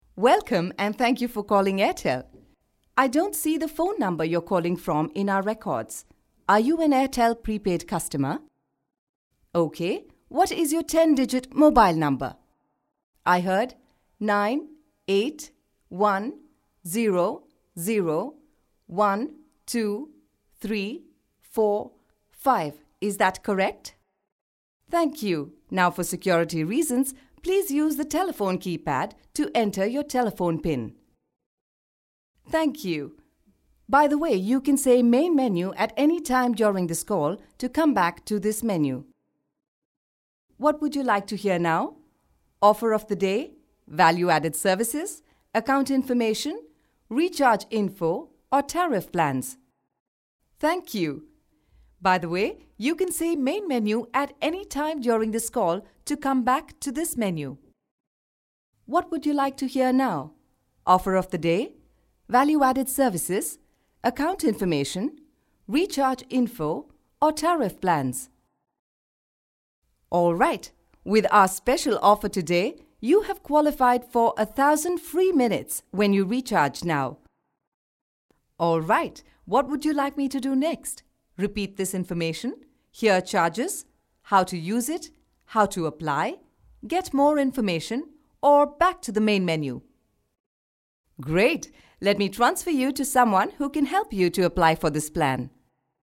britisch
Sprechprobe: Sonstiges (Muttersprache):
female voice over talent english (uk).